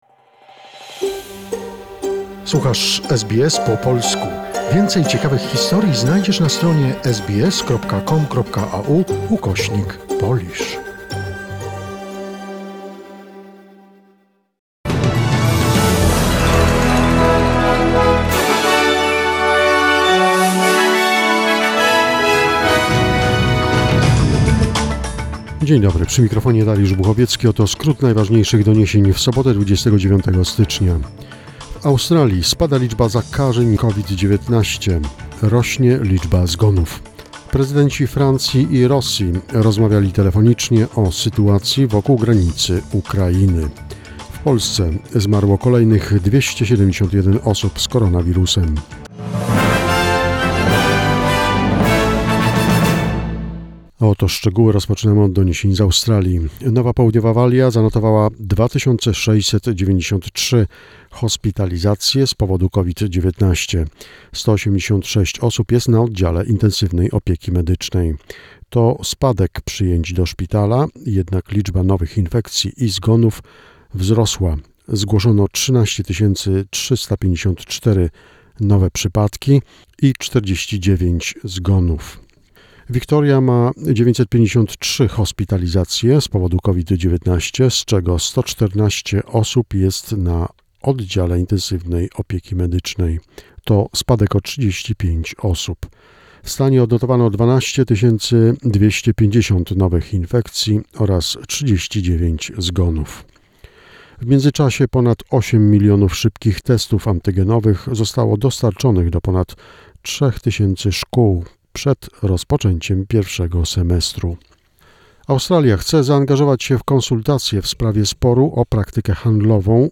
SBS News Flash in Polish, 29 January 2022